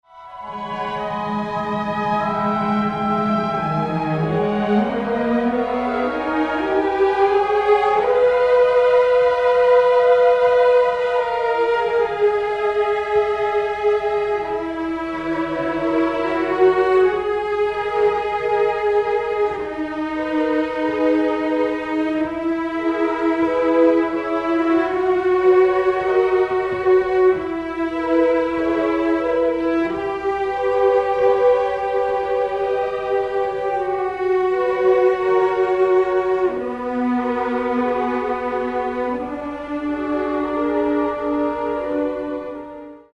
3-3-3-2, 4-2-3-1, Timp., Perc. (3), Hp, Str.